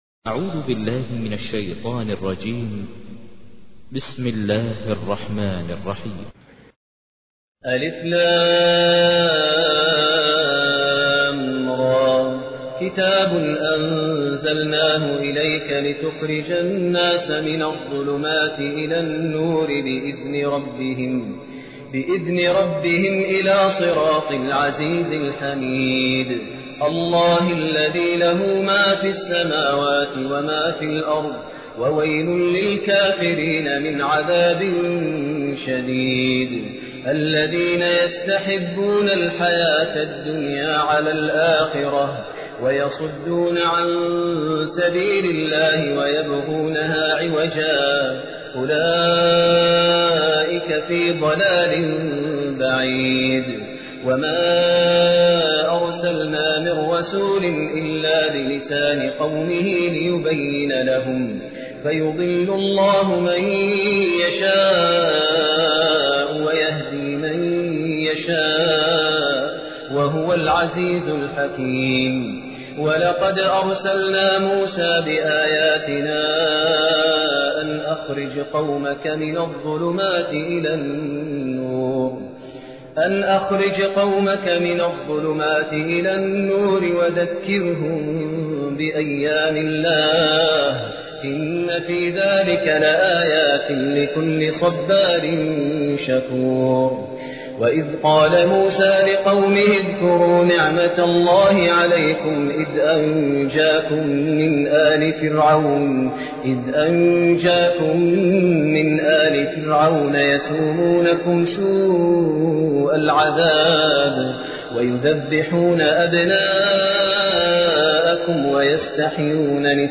تحميل : 14. سورة إبراهيم / القارئ ماهر المعيقلي / القرآن الكريم / موقع يا حسين